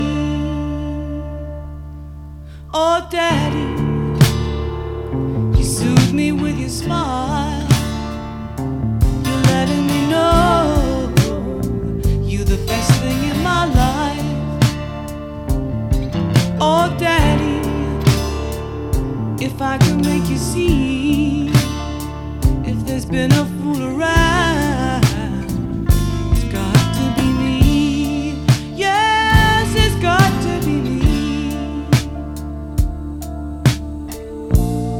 # Рок